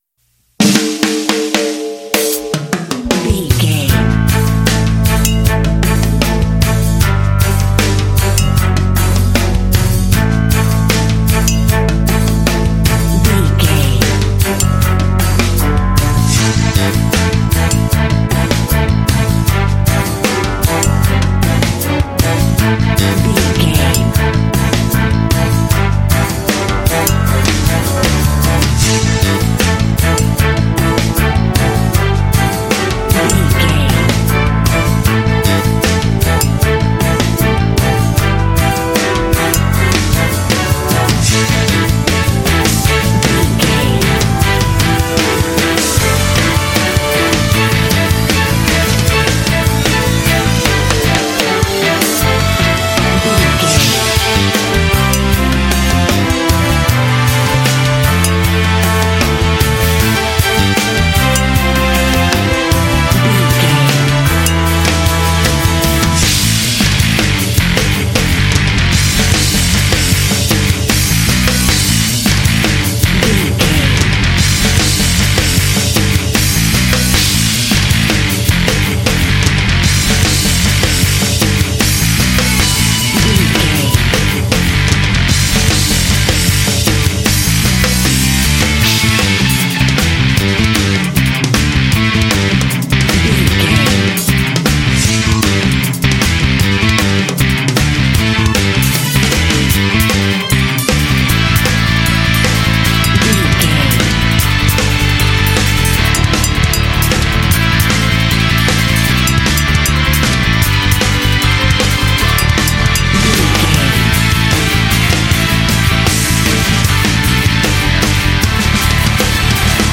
Epic / Action
Dorian
funky
groovy
dark
energetic
aggressive
drums
piano
bass guitar
strings
cinematic
symphonic rock